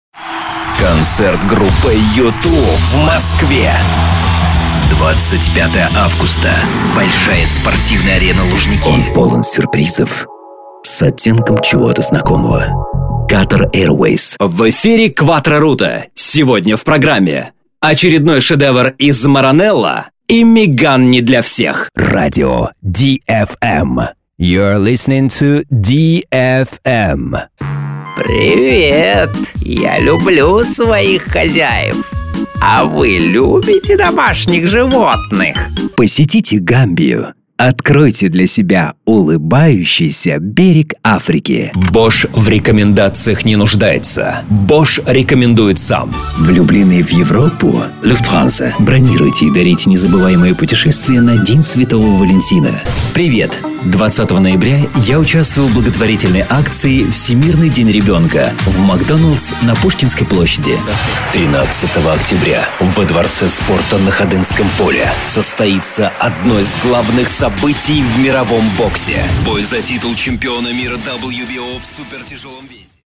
Masculino
Commercial, ads spots
Rich, warm, cool, young, adult